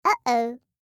알림음 8_HobbitOhaw.mp3